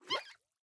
Minecraft Version Minecraft Version snapshot Latest Release | Latest Snapshot snapshot / assets / minecraft / sounds / mob / axolotl / idle_air1.ogg Compare With Compare With Latest Release | Latest Snapshot
idle_air1.ogg